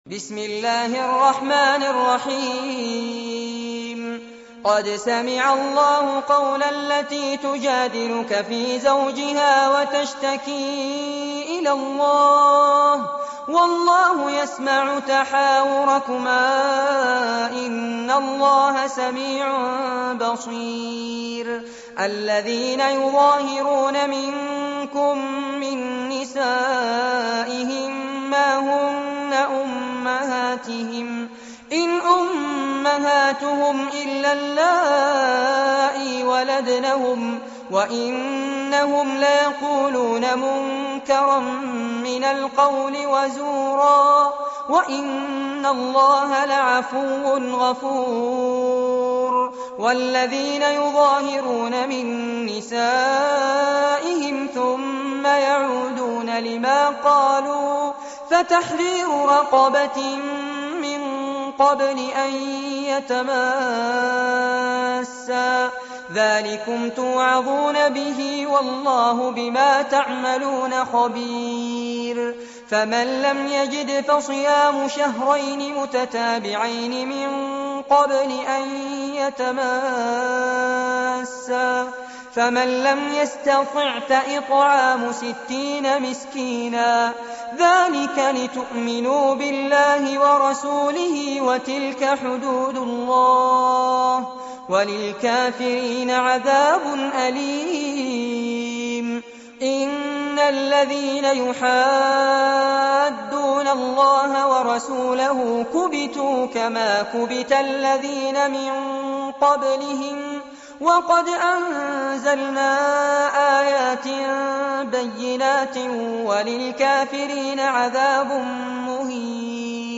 عنوان المادة سورة المجادلة- المصحف المرتل كاملاً لفضيلة الشيخ فارس عباد جودة عالية